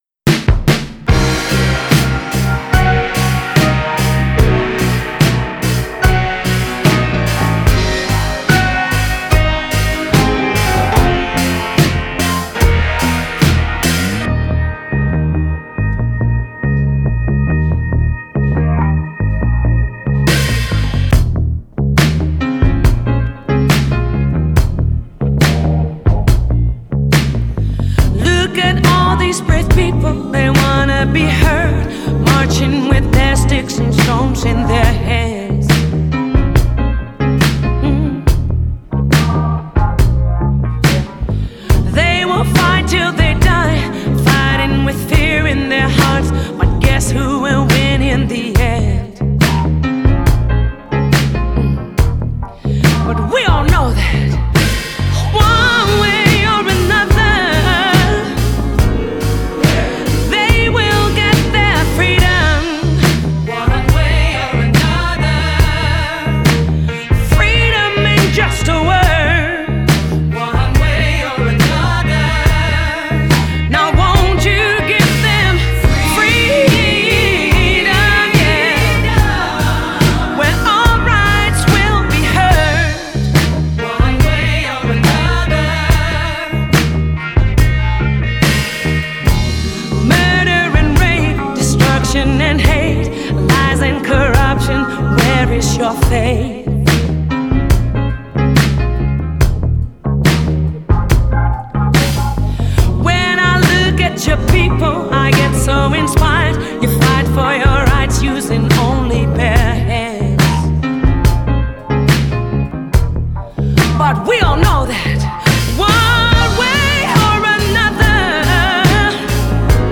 Genre: Pop / Soul / Jazz